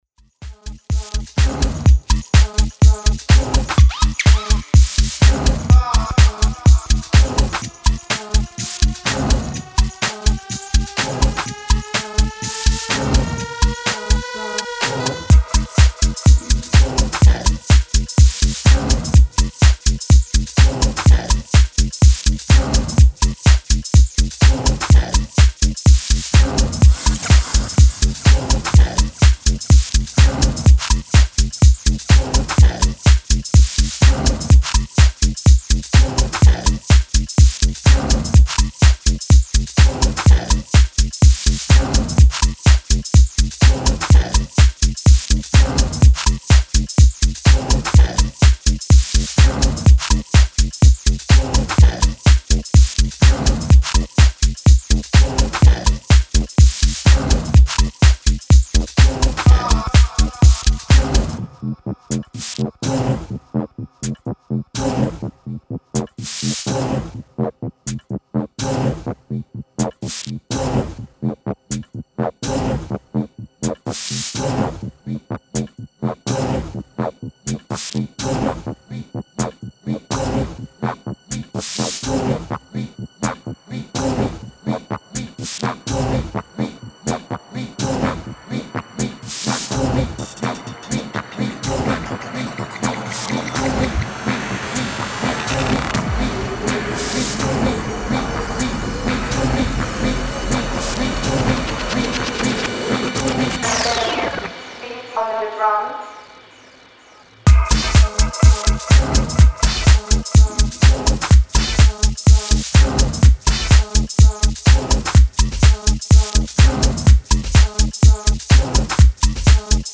delivers a top notch techy version
Style: House